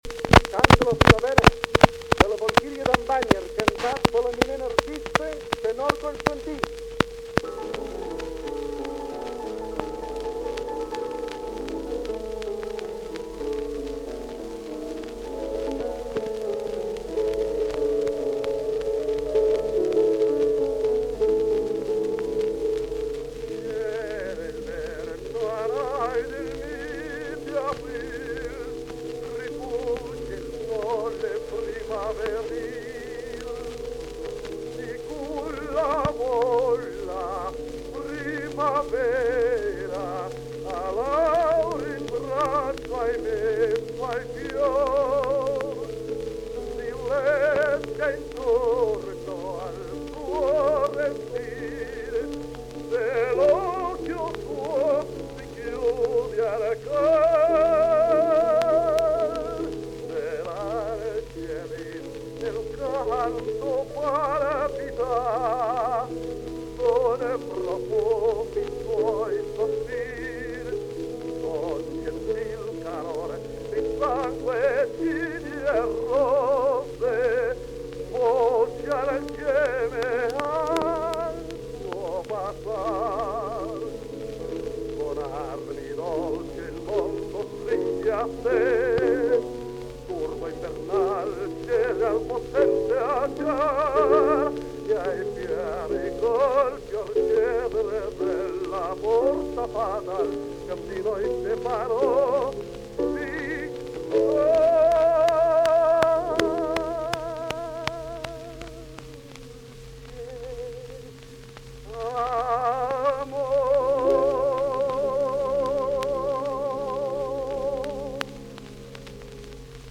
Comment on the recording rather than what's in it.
In two parts, on two cylinders.